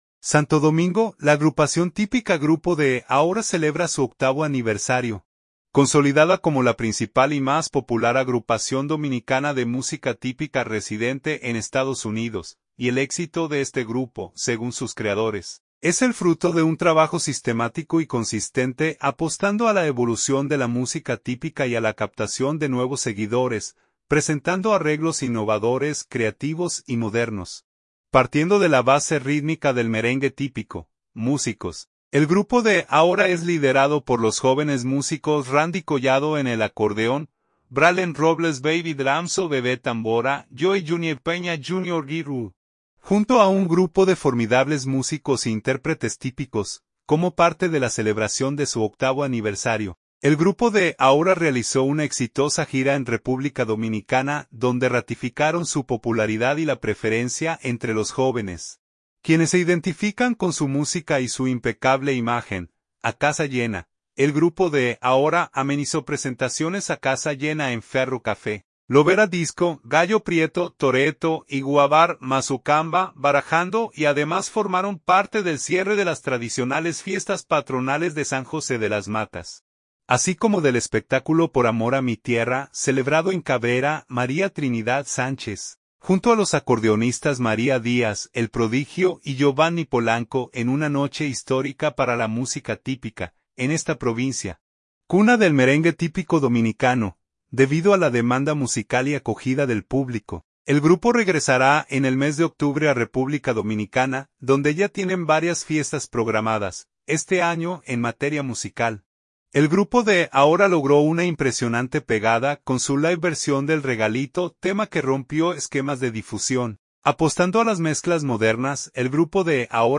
acordeón